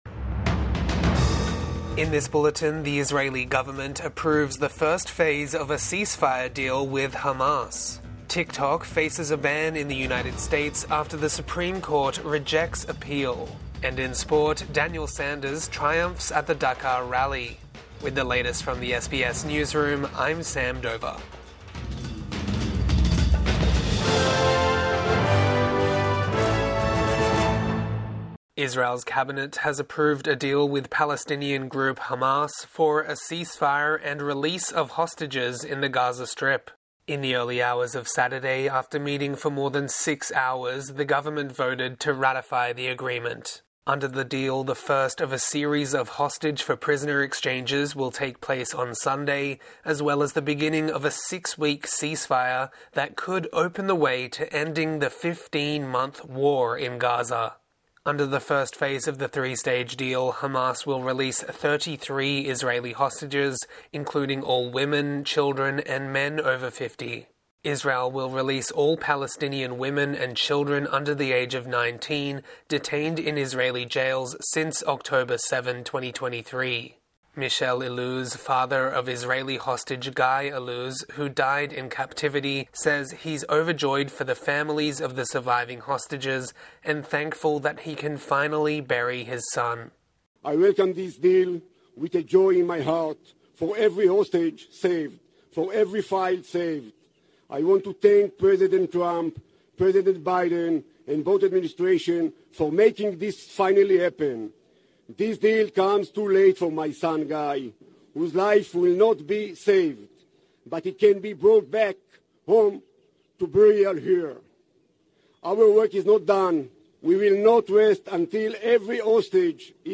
Midday News Bulletin 18 January 2025